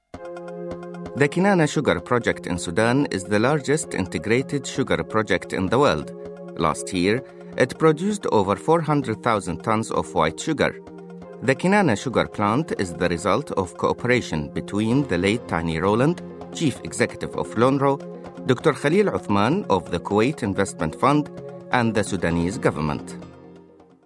Arabic (Iraq), Middle Eastern, Male, 30s-50s